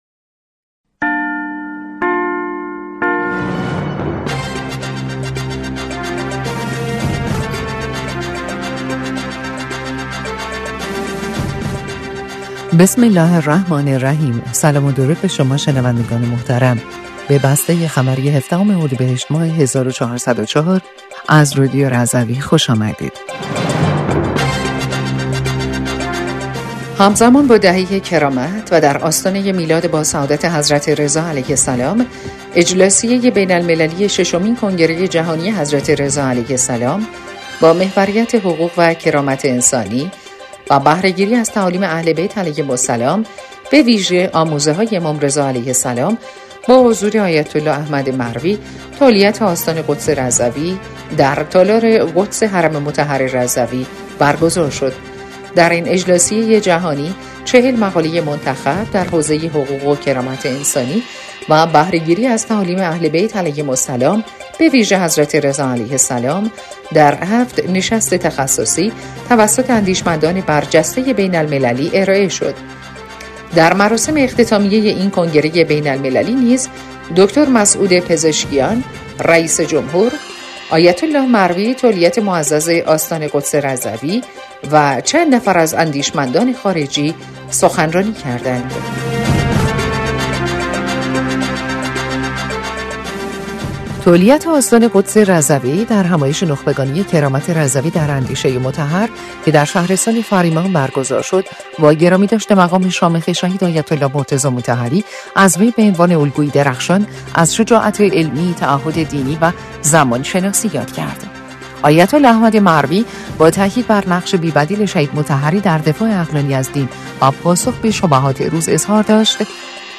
بسته خبری ۱۷ اردیبهشت ۱۴۰۴ رادیو رضوی؛